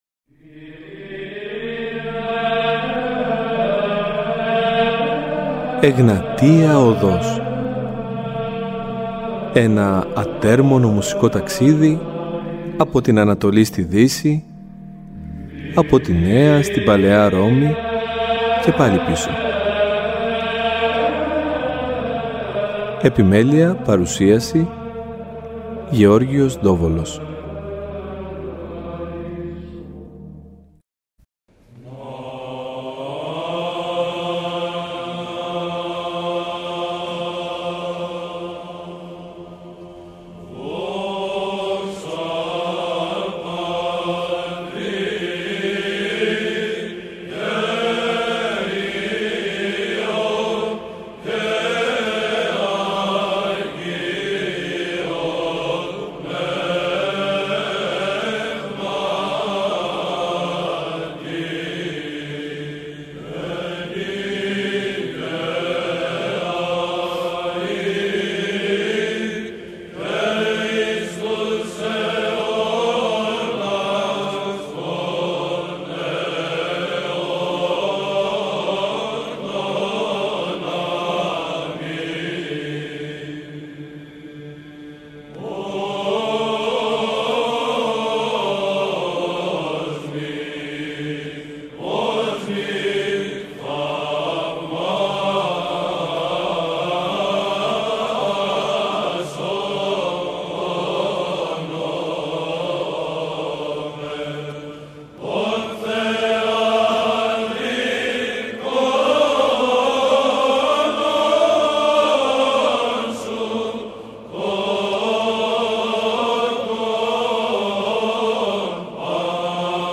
Συζητουν επίσης για το ρόλο της ψαλτικής τέχνης στο σήμερα και για την απήχηση της στο σύγχρονο άνθρωπο. Παράλληλα θα ακουστούν επίκαιροι ύμνοι από την υμνολογία των ημερών.